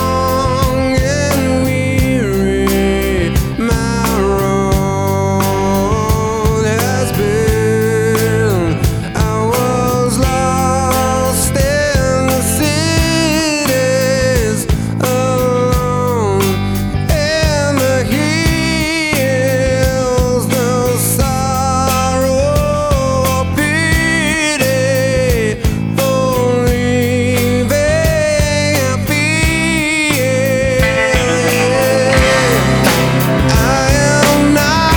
Hard Rock Rock Metal Alternative Grunge
Жанр: Рок / Альтернатива / Метал